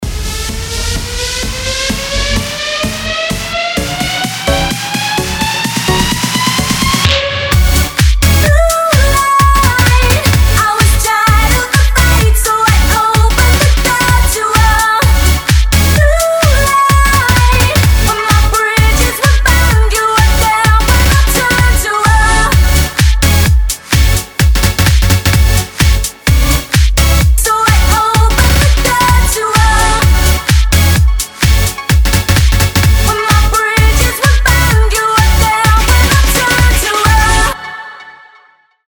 • Качество: 320, Stereo
dance
club
vocal
Танцевальный хит с потрясающим вокалом!